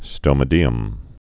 (stōmə-dēəm)